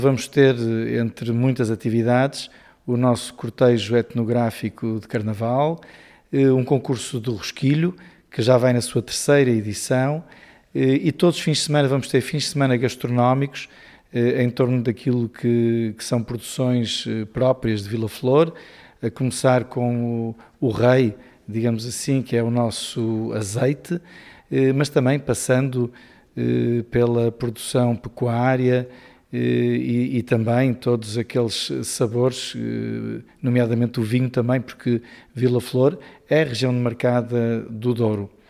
O presidente da Câmara Municipal de Vila Flor, Pedro Lima, sublinha que o evento pretende valorizar o território, a cultura e os produtos locais: